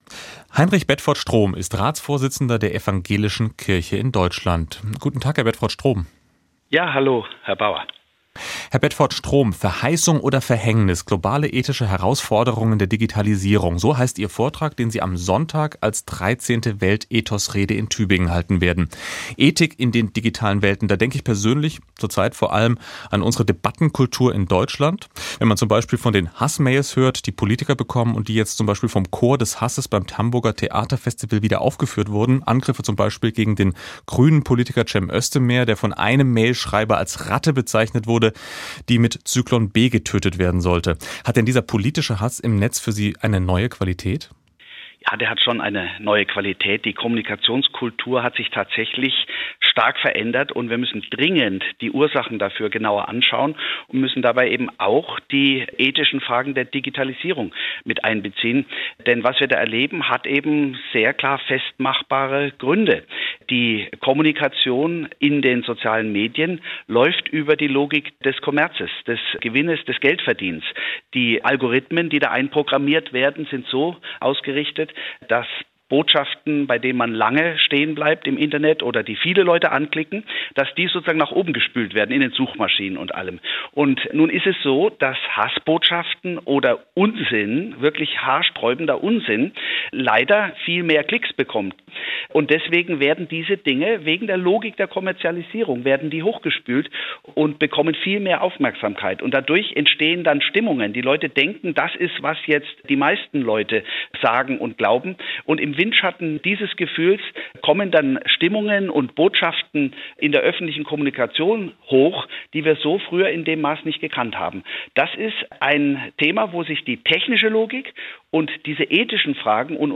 SWR2 Politisches Interview   /     Heinrich Bedford-Strohm hält Tübinger Weltethos-Rede: Weltethos im Internet
ekd-ratsvorsitzender-heinrich-bedford-strohm-haelt-tuebinger-weltethos-rede-2018-interview.m.mp3